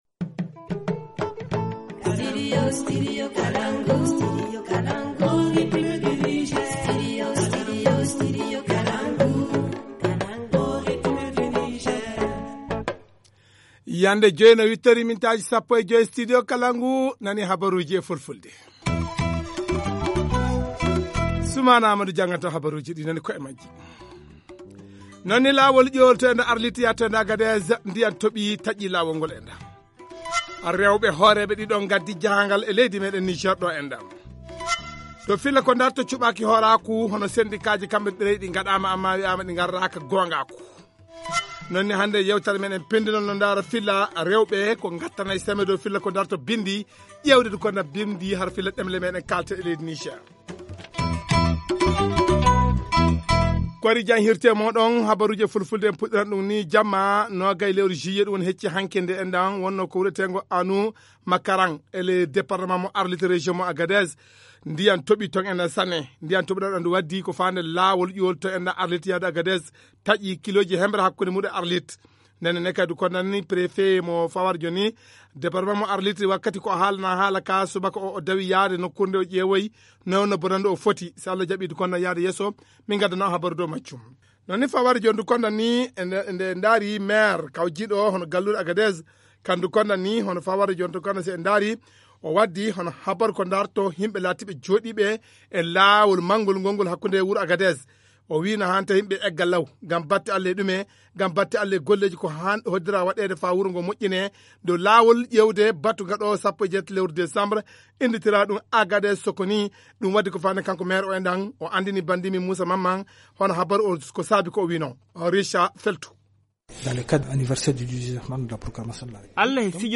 Journal en français.